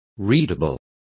Complete with pronunciation of the translation of readable.